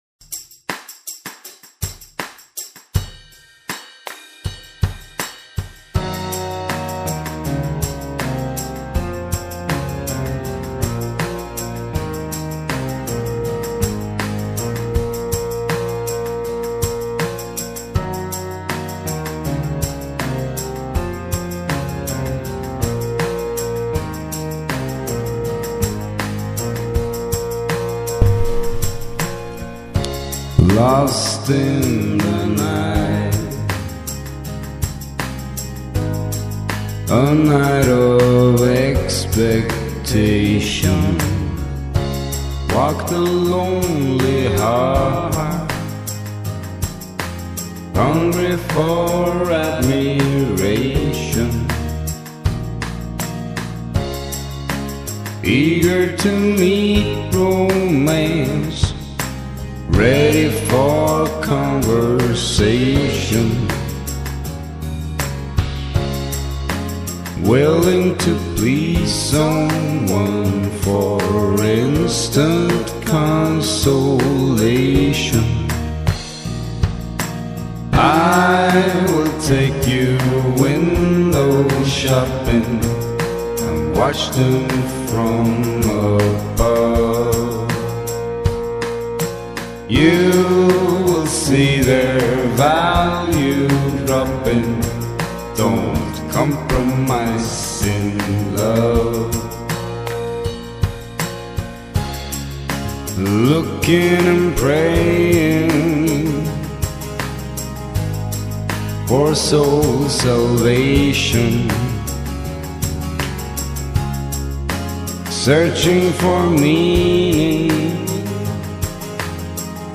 (Ballad)